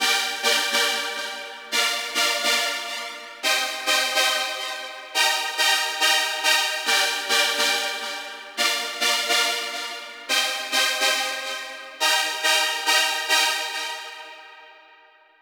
VTDS2 Song Kit 15 Pitched Freaking Synth Harmonic.wav